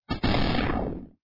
enemy2_down.wav